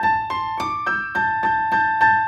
GS_Piano_105-A2.wav